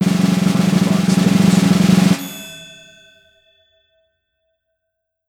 Snare Roll
“RollRing” Clamor Sound Effect
Can also be used as a car sound and works as a Tesla LockChime sound for the Boombox.